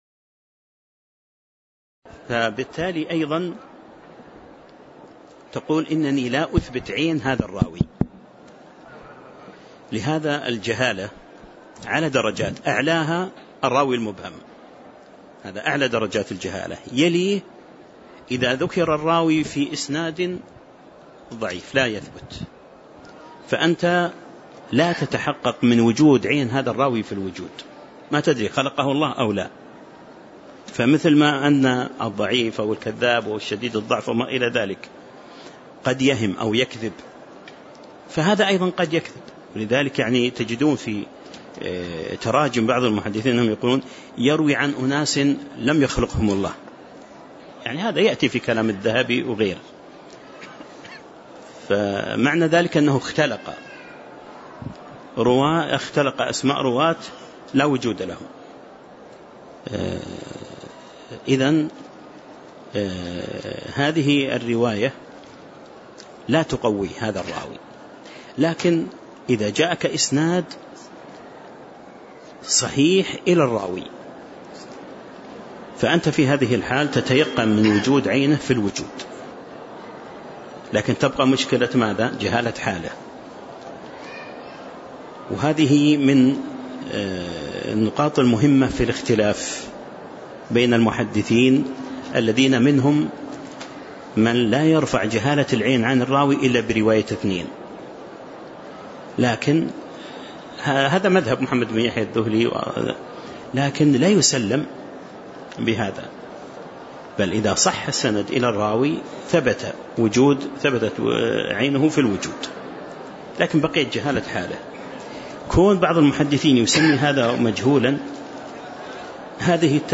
تاريخ النشر ١٣ صفر ١٤٣٨ هـ المكان: المسجد النبوي الشيخ